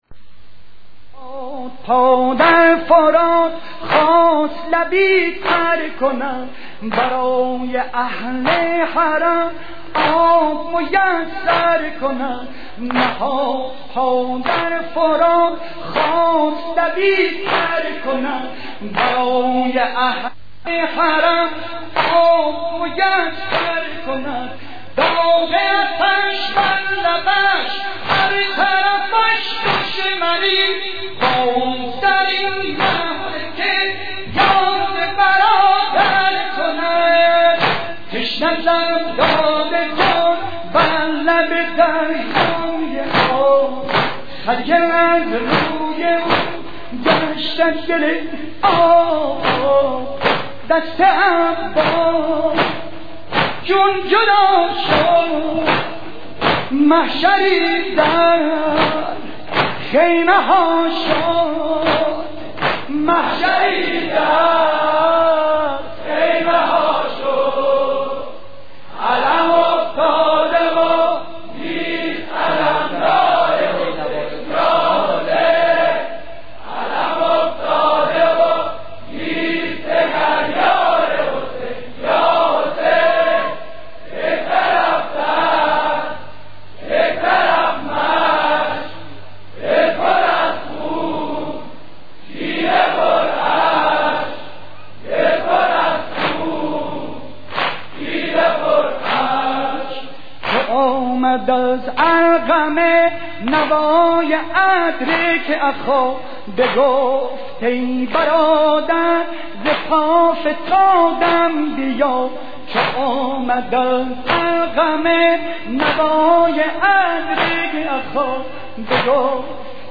نوحه خوانی